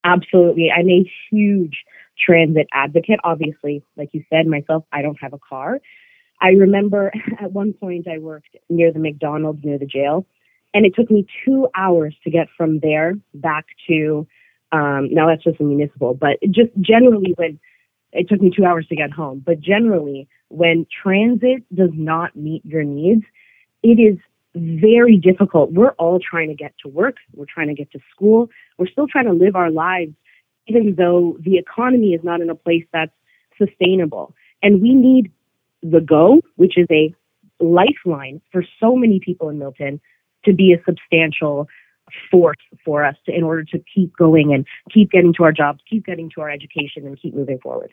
Here’s our interview: